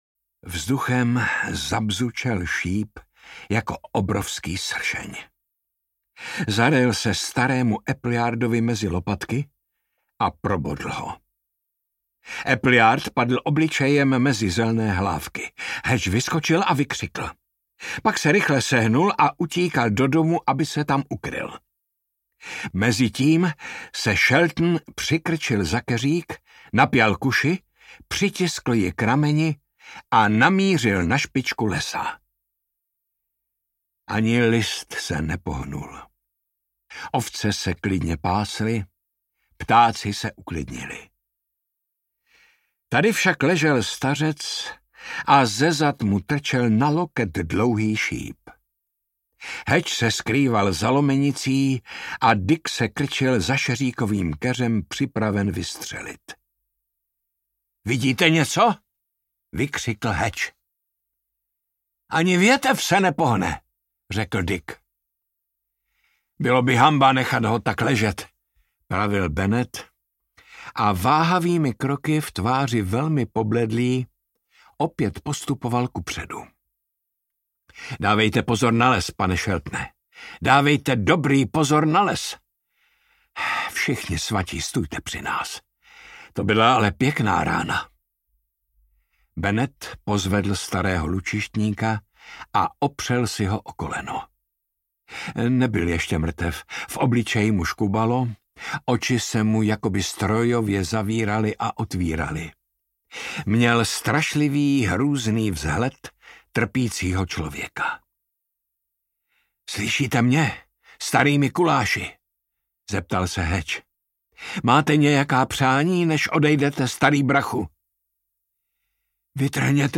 Černý šíp audiokniha
Ukázka z knihy
Čte Jaromír Meduna.
• InterpretJaromír Meduna